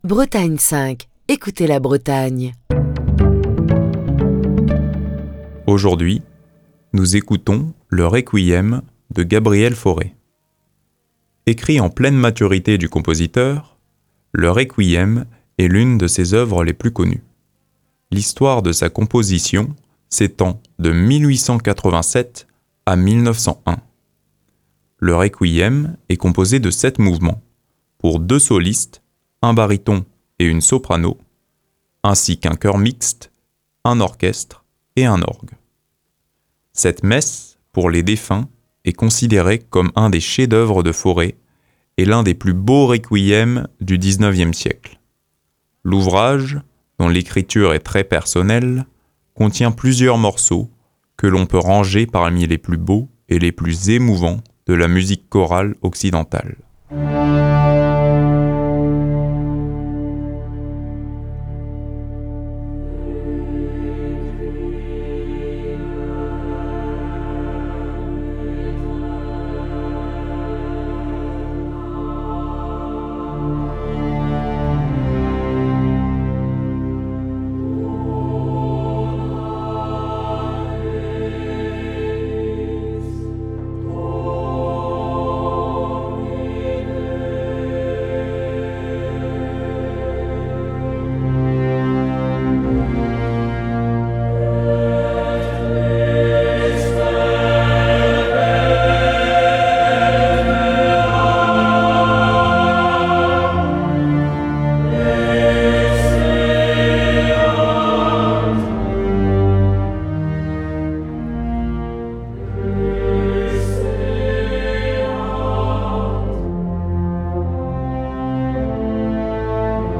Le Requiem est composé de sept mouvements, pour deux solistes (baryton et soprano), chœur mixte, orchestre et orgue. Cette messe pour les défunts est considérée comme un des chefs-d'œuvre de Fauré et l'un des plus beaux Requiem du 19ème siècle.
Nous écoutons ce matin Le Kyrie, introduction du requiem de Fauré, pleine de ferveur religieuse.
Il est interprété ici par l’ensemble Aedes, accompagné de l’orchestre Les Siècles, dans une version de 2019.
ensemble vocal français
Les musiciens de cet orchestre jouent chaque répertoire sur les instruments historiques appropriés.